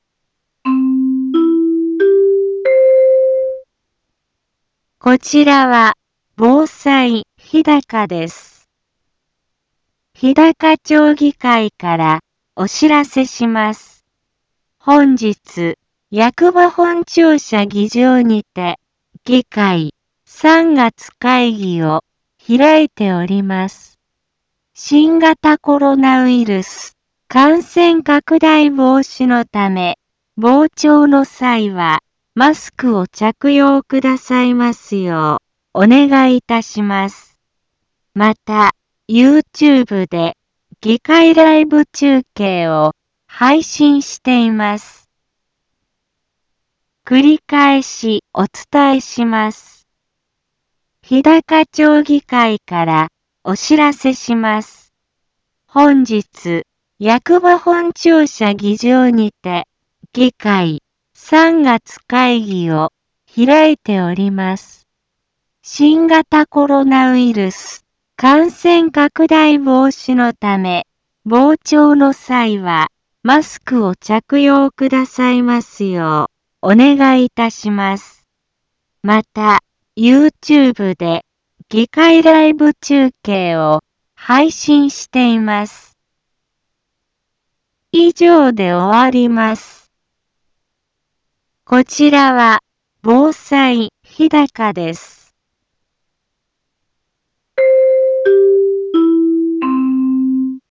Back Home 一般放送情報 音声放送 再生 一般放送情報 登録日時：2022-03-07 10:03:34 タイトル：日高町議会３月会議のお知らせ インフォメーション：こちらは防災日高です。